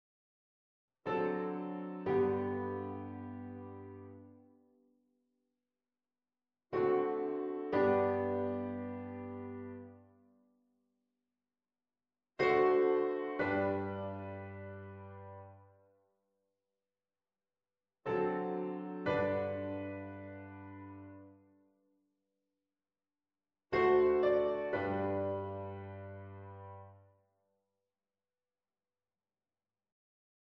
afspringende leidtoon en stijgend septime